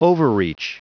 Prononciation du mot overreach en anglais (fichier audio)
Prononciation du mot : overreach